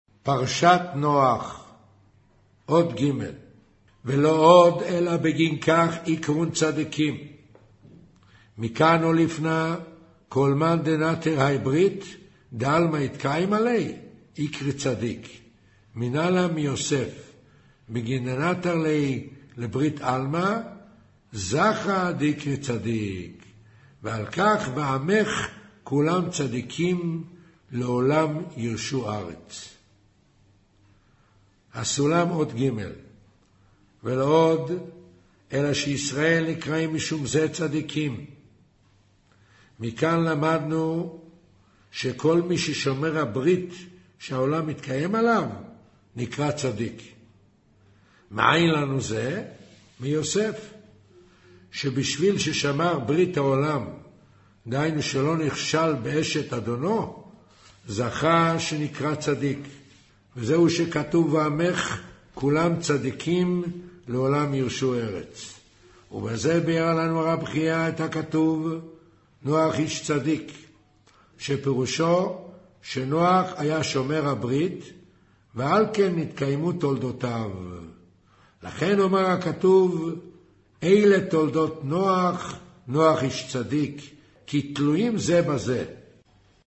אודיו - קריינות זהר